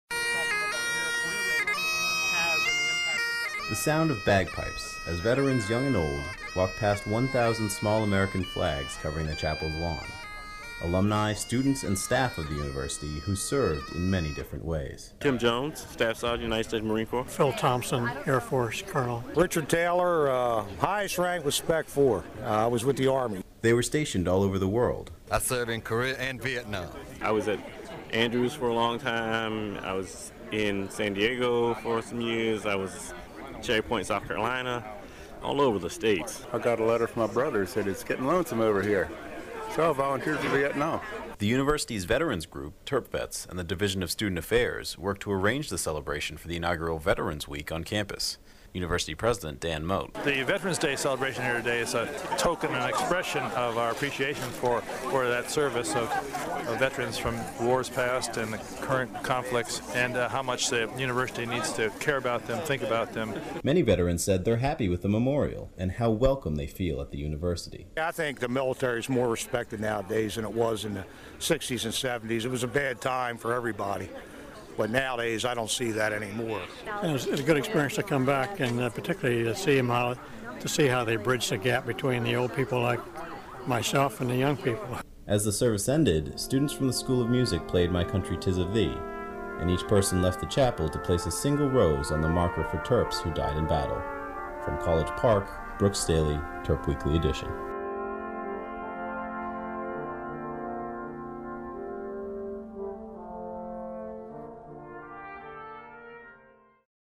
First Place Radio II – News - Hearst Journalism Awards Program